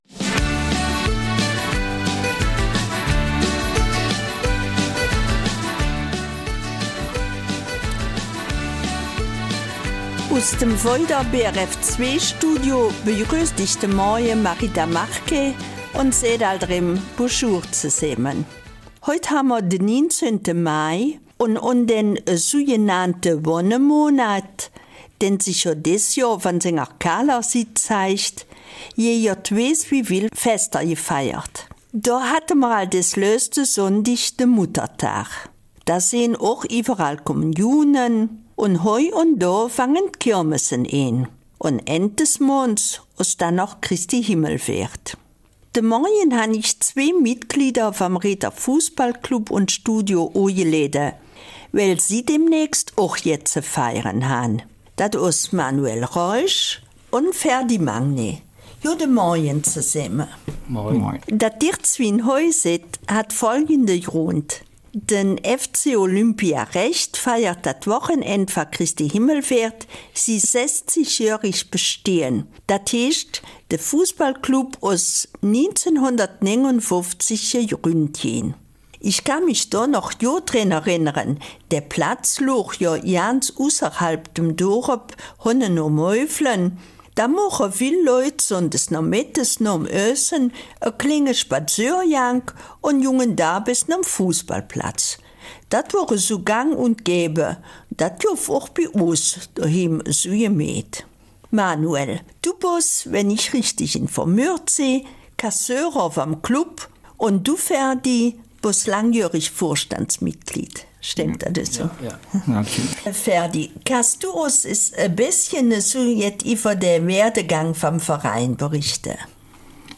Da die Festivitäten zu dem Jubiläum auf drei Tage verteilt sind (30. Mai, 31. Mai und 1. Juni), werden uns in dem Gespräch alle Details mitgeteilt werden.